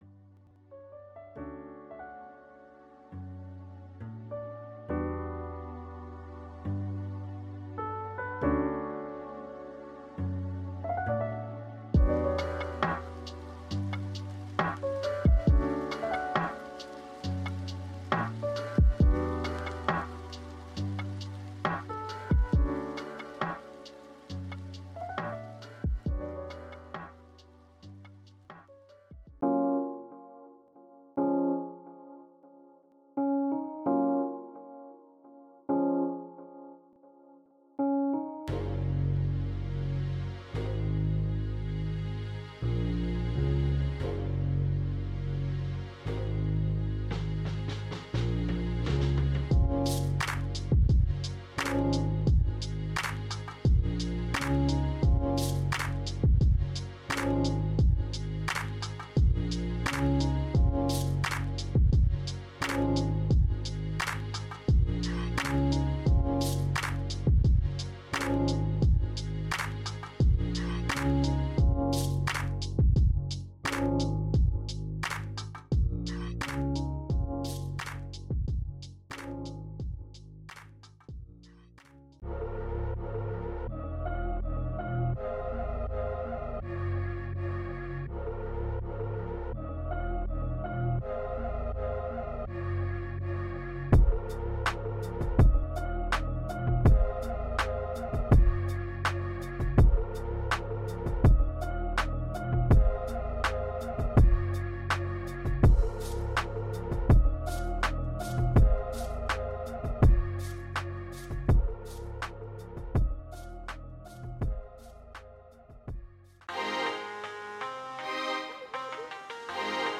سمپل پک چیل هاپ